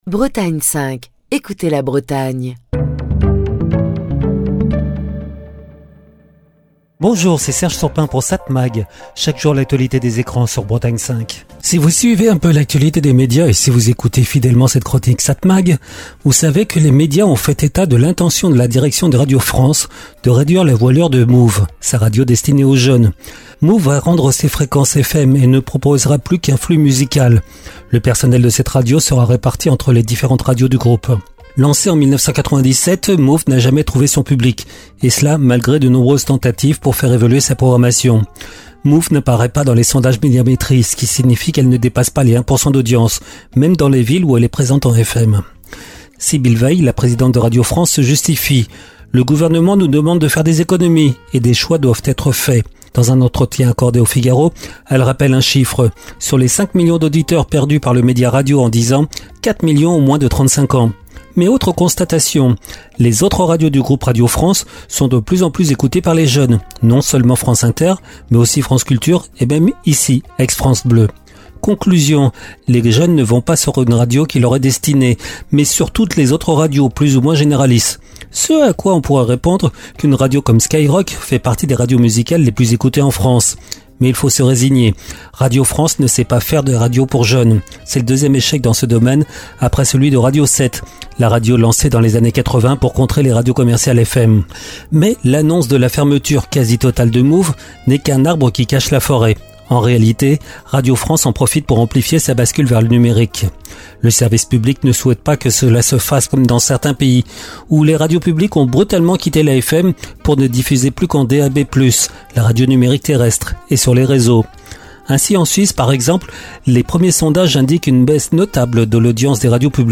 Chronique du 1er mai 2025.